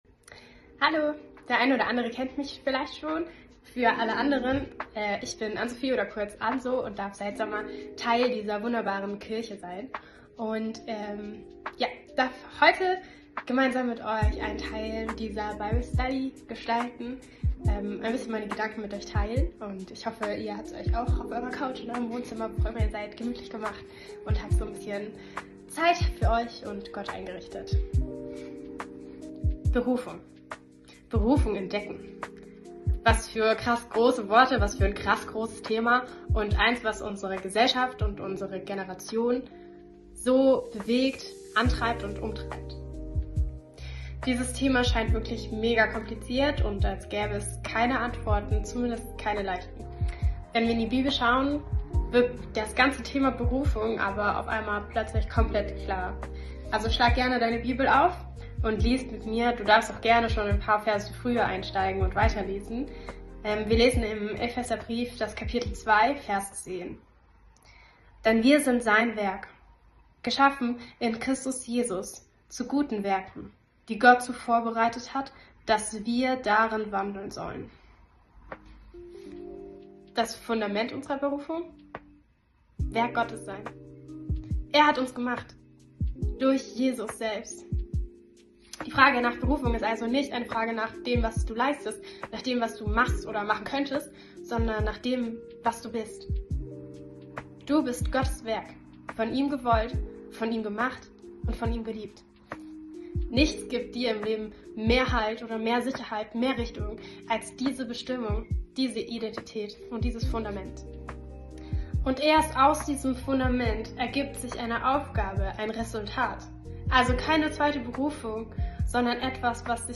Tag 19 der Andacht zu unseren 21 Tagen Fasten & Gebet